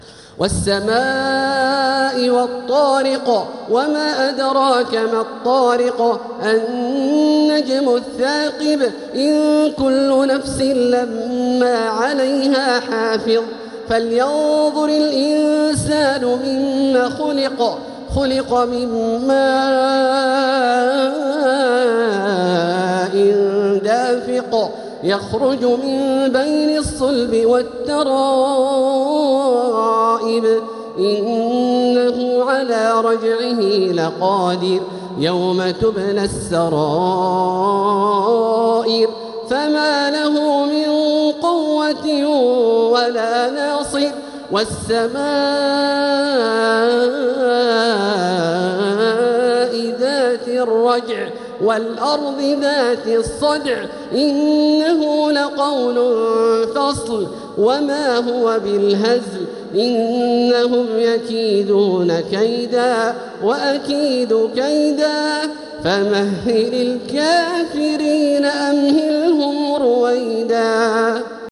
سورة الطارق | مصحف تراويح الحرم المكي عام 1446هـ > مصحف تراويح الحرم المكي عام 1446هـ > المصحف - تلاوات الحرمين